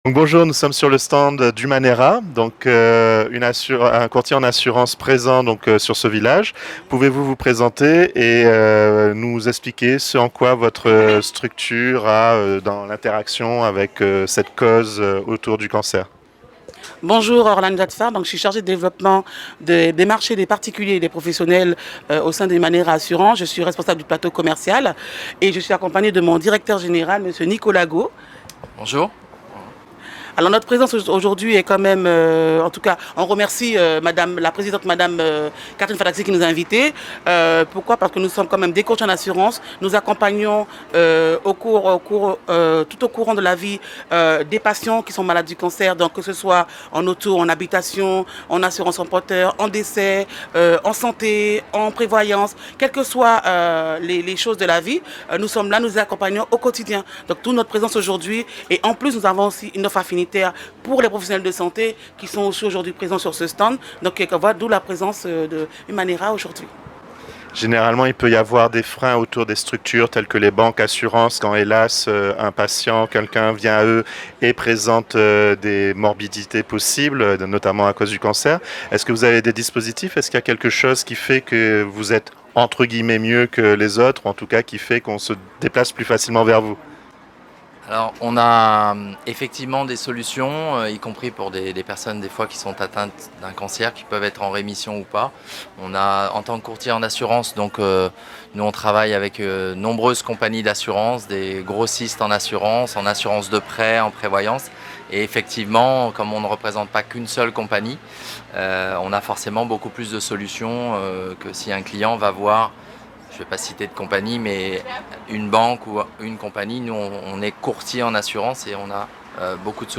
au village d'Accompagnement à Rémire-Montjoly - Rencontre